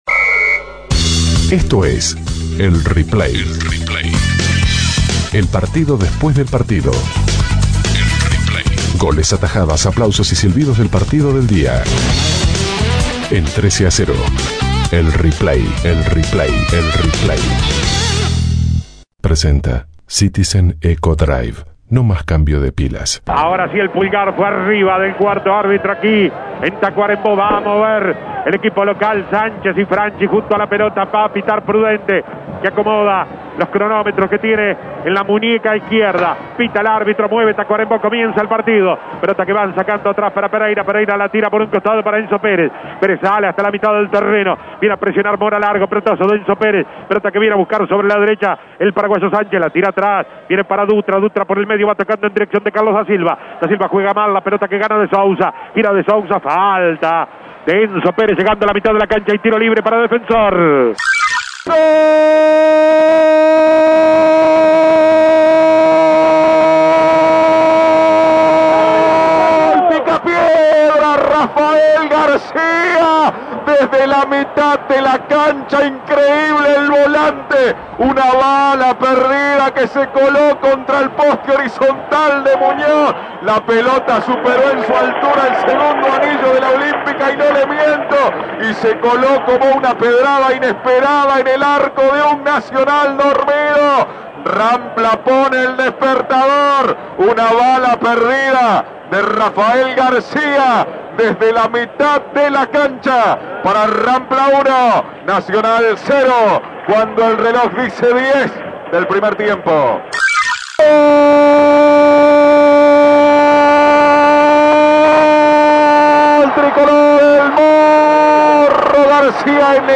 Escuche los goles y las principales incidencias con los relatos